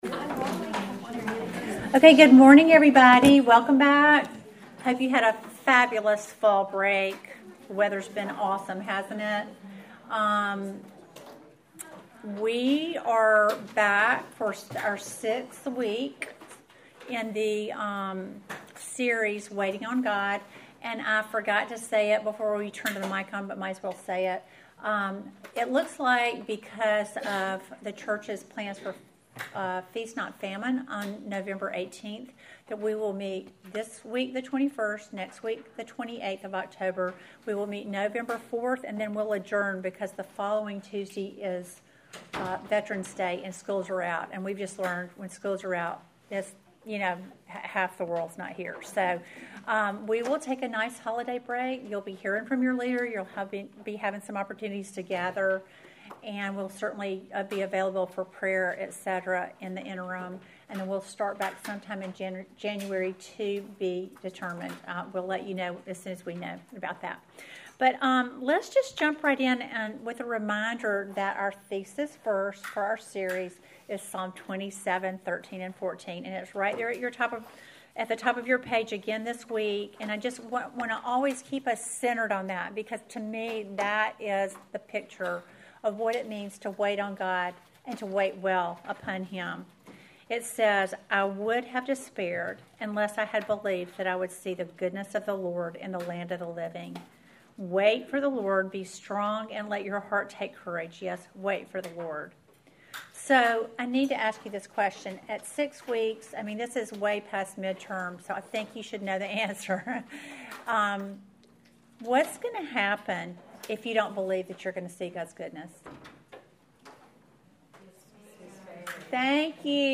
Welcome to the sixth lesson in our series WAITING ON GOD!